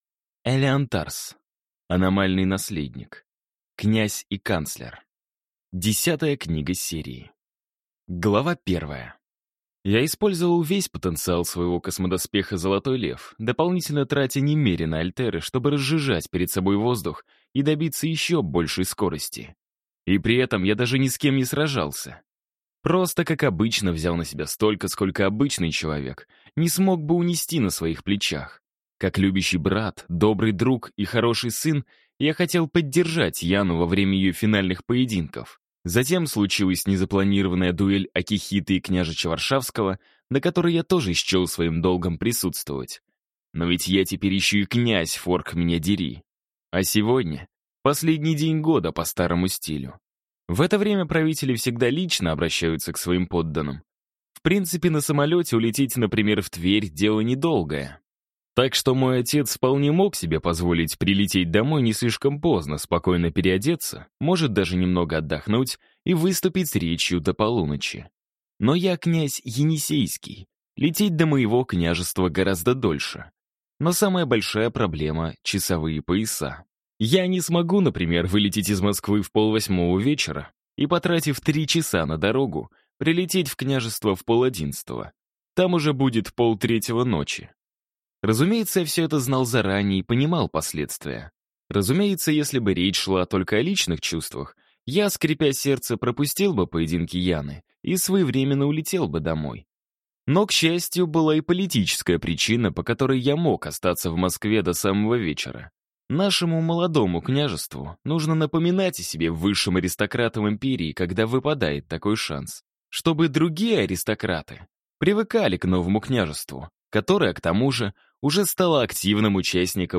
Аудиокнига Аномальный Наследник. Князь и Канцлер | Библиотека аудиокниг